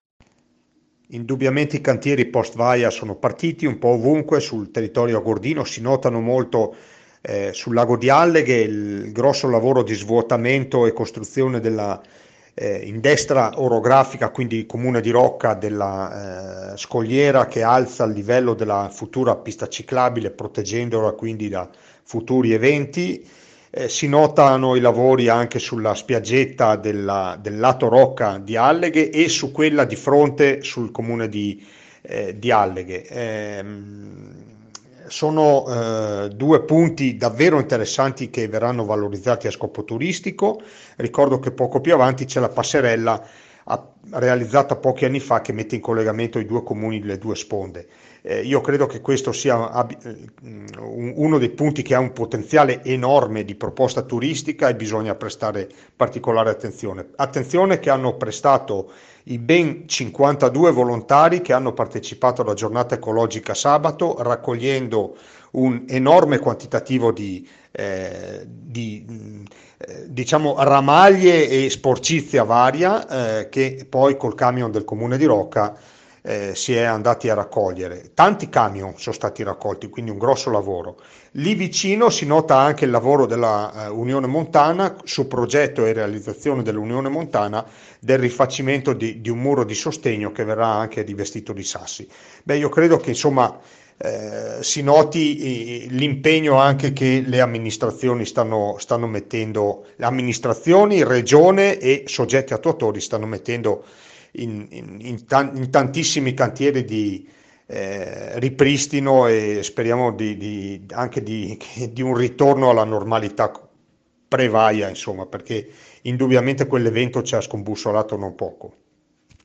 AUDIO IL SINDACO DE BERNARDIN